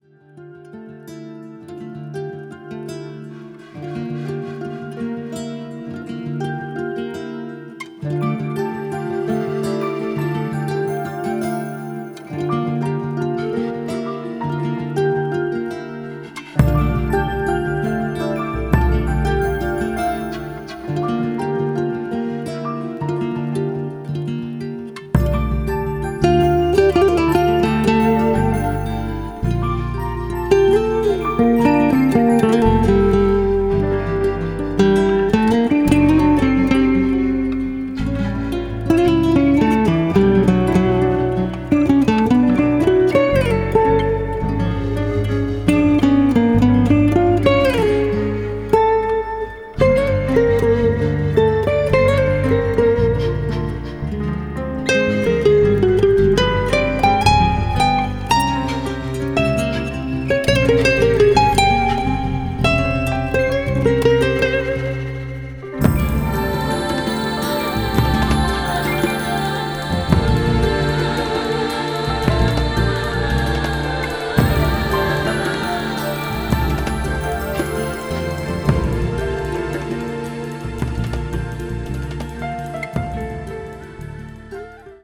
Imagine a single 6 watt vacuum tube driving a single 8 inch
You'll be listening to recordings of a loudspeaker.
Spacious guitar work